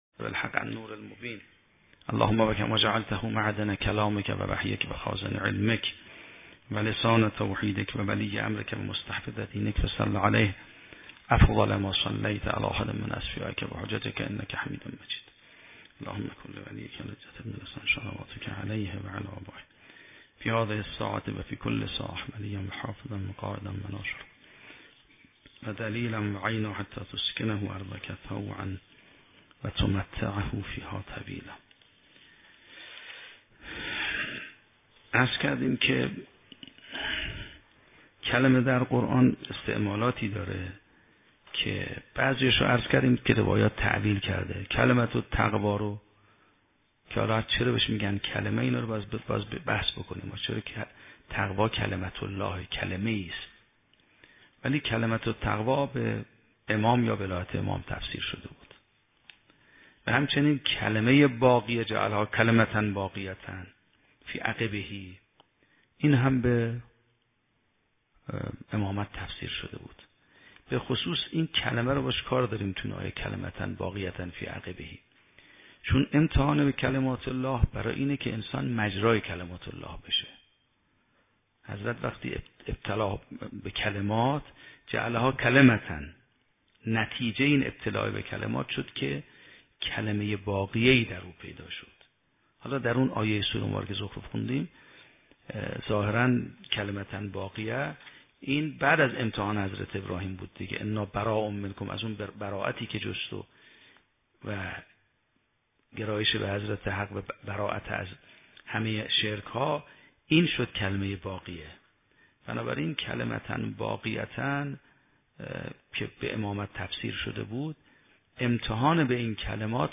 شرح و بررسی کتاب الحجه کافی توسط آیت الله سید محمدمهدی میرباقری به همراه متن سخنرانی ؛ این بخش : کلمات الهی و علم و فضائل اهل بیت - روایات بحث احقاق حق با کلمات الهی